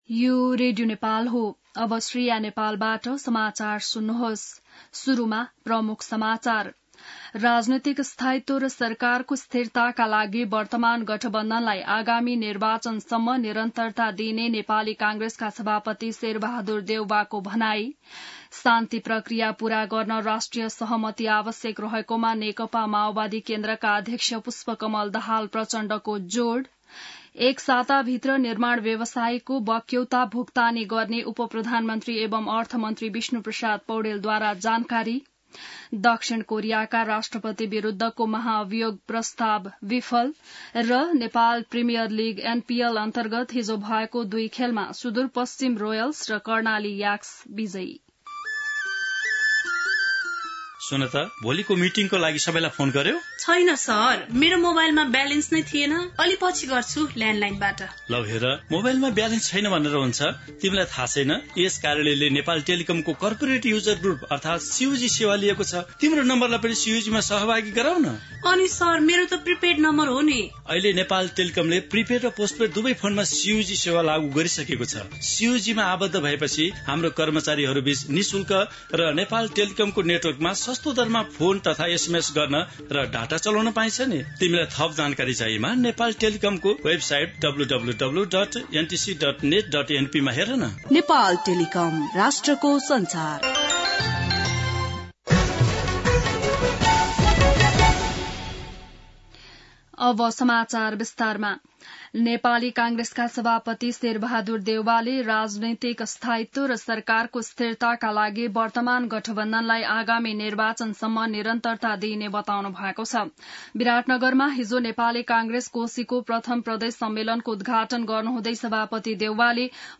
बिहान ७ बजेको नेपाली समाचार : २४ मंसिर , २०८१